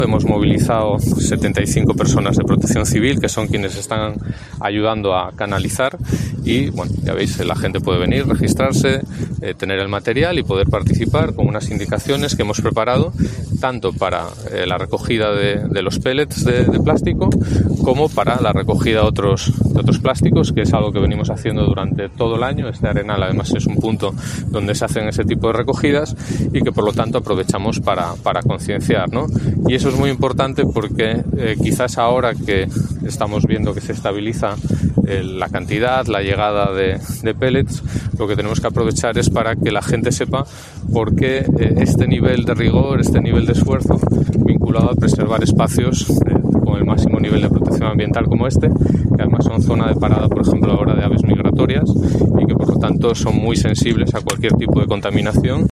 El consejero de Fomento del Principado, Alejandro Calvo, sobre la llegada de pellets a Asturias